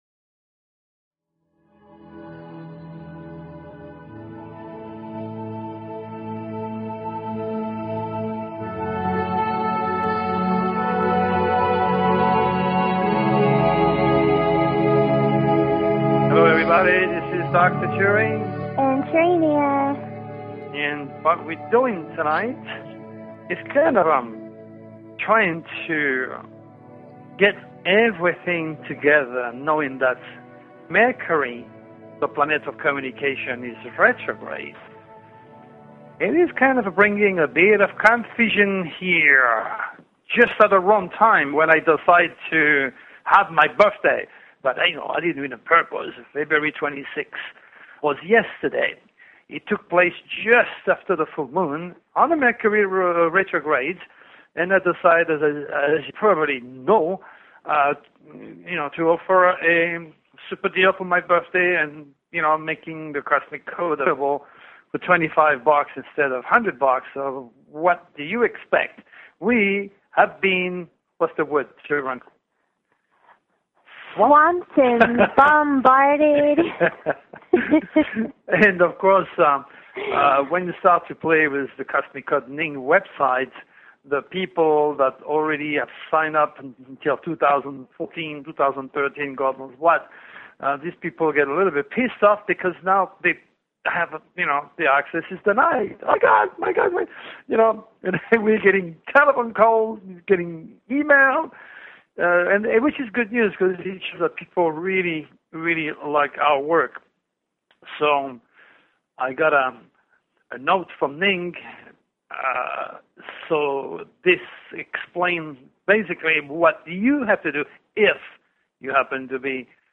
Talk Show Episode, Audio Podcast, The_Cosmic_Code and Courtesy of BBS Radio on , show guests , about , categorized as